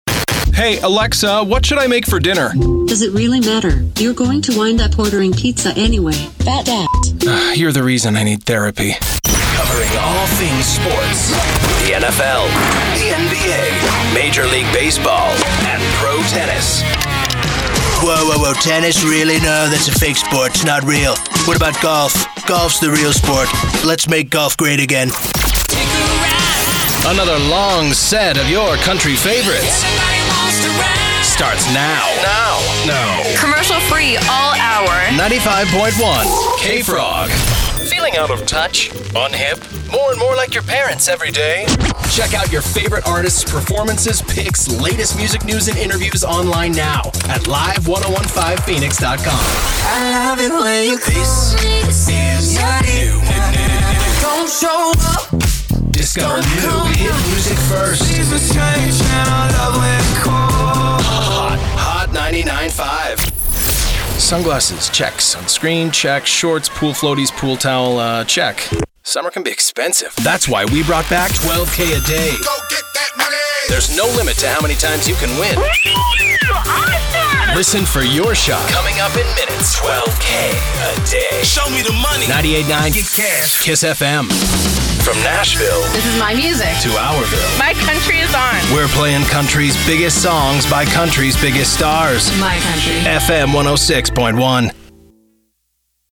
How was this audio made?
Professionally-built, broadcast quality, double-walled LA Vocal Booth.